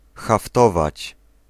Ääntäminen
IPA : /ˈpjuːk/